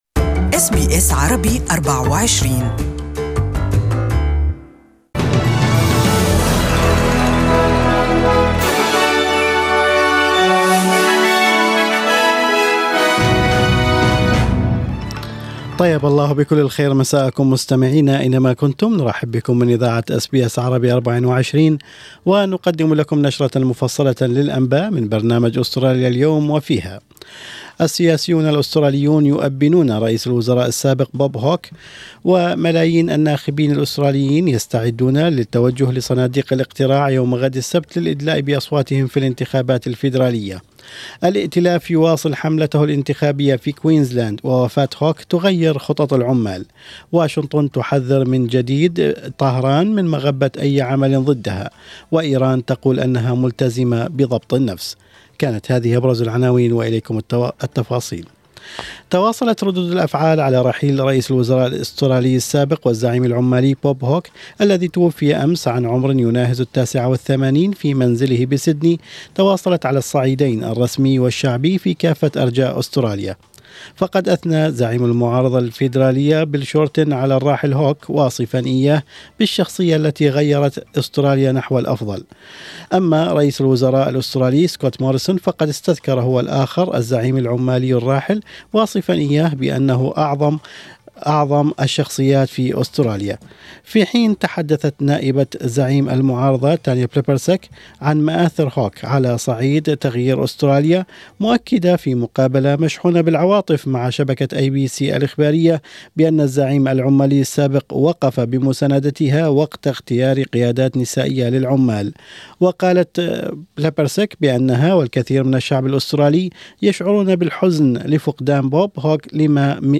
Evening News from SBS Arabic24